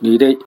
Cdo-fzho_20_(nê-sĕk).ogg